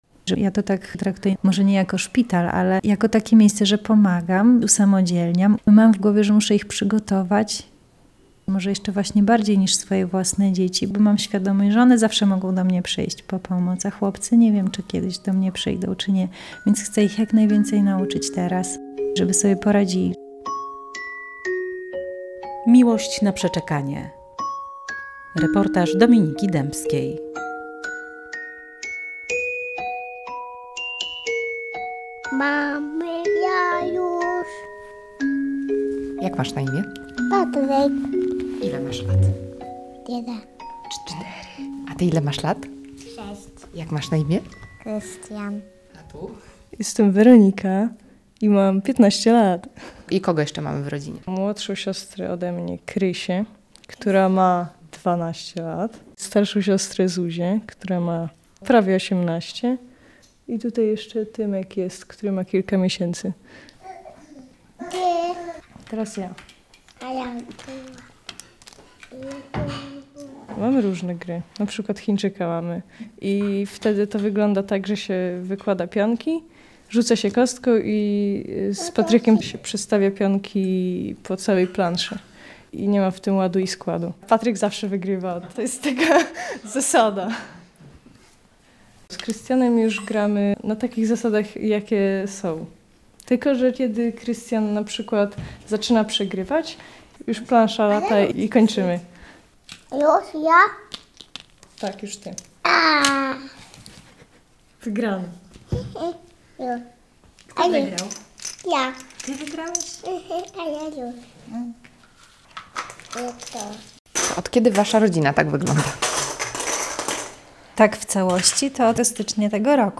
"Miłość na przeczekanie" - reportaż